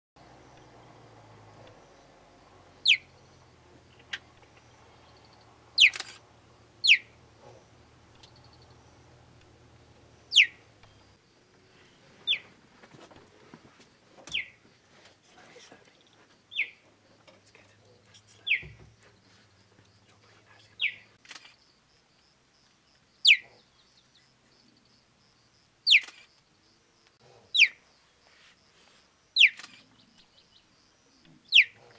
На этой странице собраны звуки гепарда в естественной среде обитания: от грозного рыка до нежного мурлыканья детенышей.
Звуки гепарда: птичий свист среди хищников